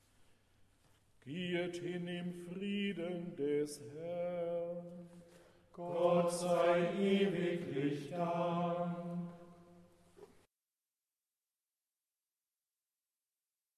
B I T T E   B E A C H T E N S I E : Diese Gesänge sind nur zu Übungszwecken hier eingestellt.
Sie sind nicht von einer professionellen Schola gesungen und auch nicht unter Studiobedingungen aufgenommen.